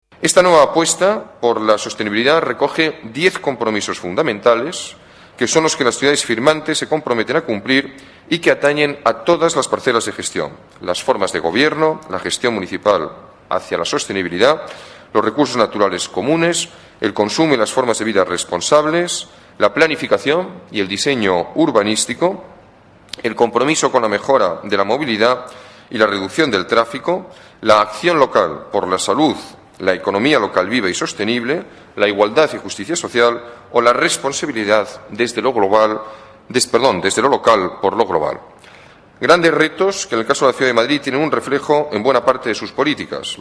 Nueva ventana:Declaraciones del alcalde